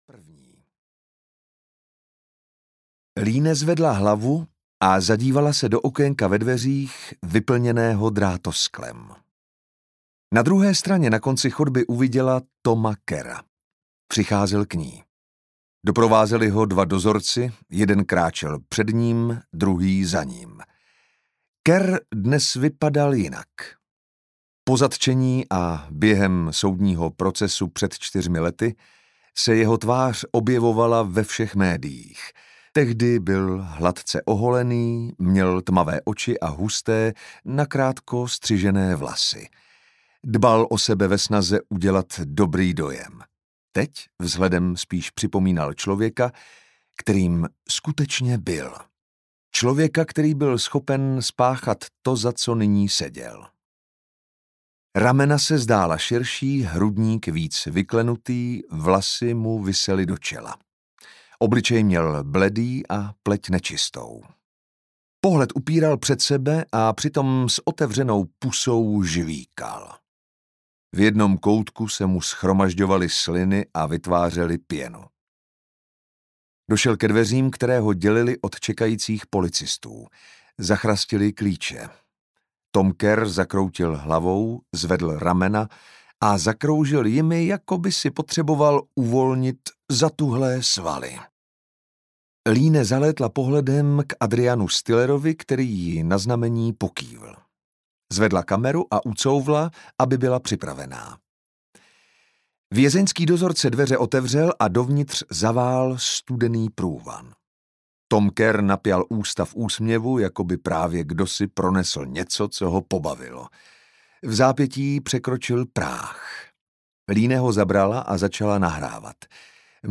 Zlovůle audiokniha
Ukázka z knihy